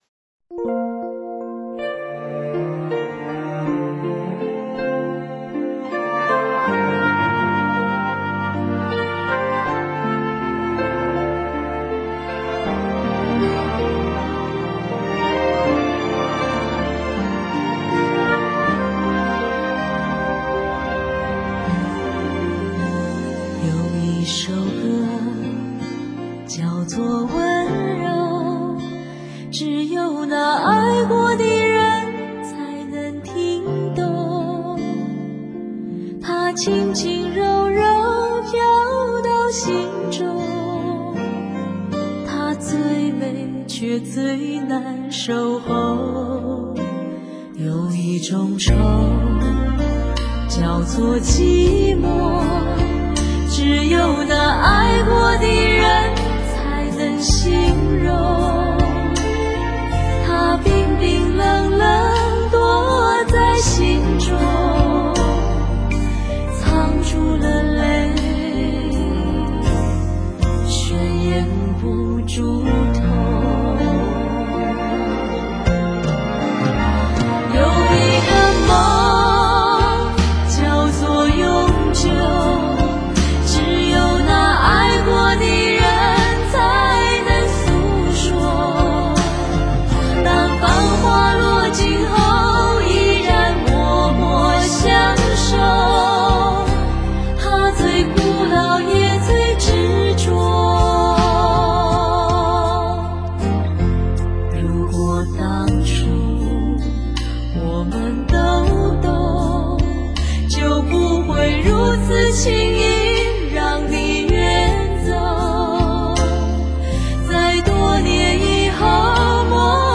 她们的演唱刚柔并济，于平淡中见大气，两人已融为一体，使听者浑然不觉。是国语歌坛中配合最好的二重唱。